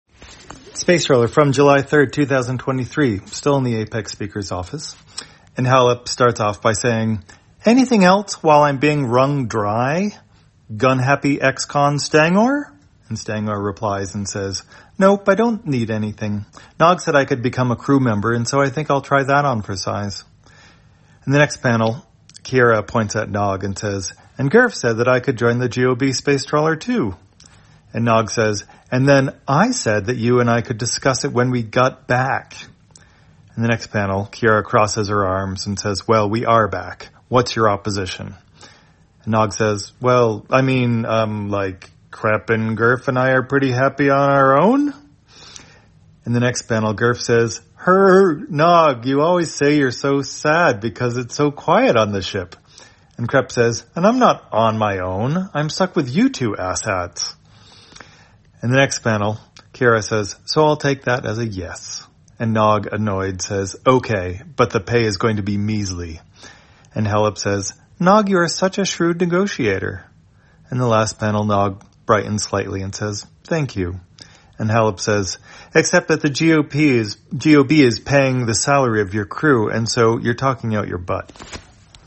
Spacetrawler, audio version For the blind or visually impaired, July 3, 2023.